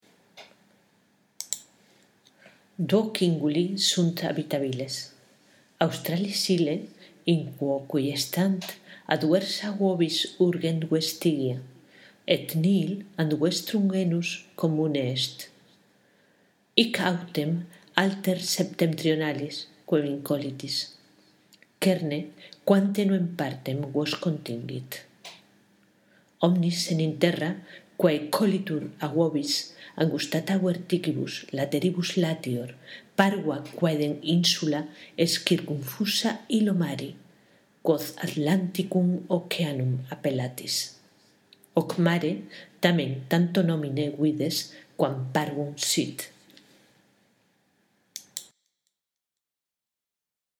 Aquí tienes una lectura que te ayudará con la pronunciación y la entonación correcta: